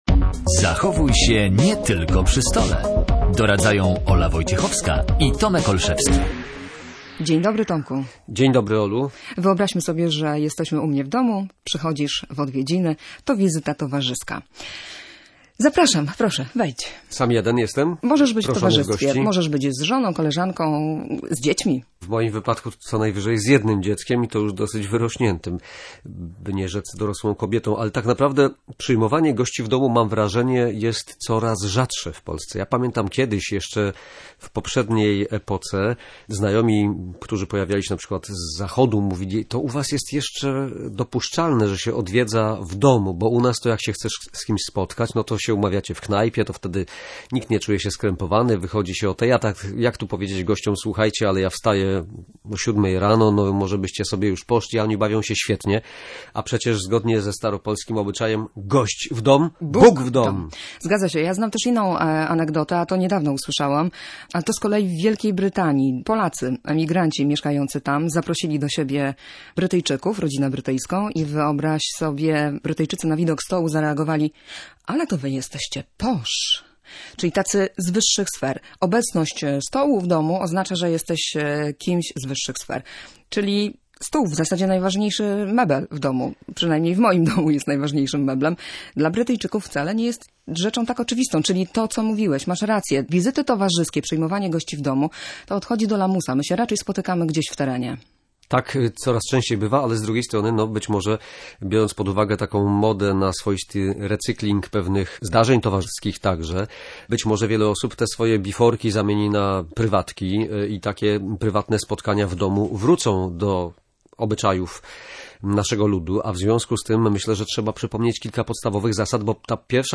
Na antenie Radia Gdańsk po raz kolejny mówiliśmy, jak się zachować.